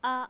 a as in father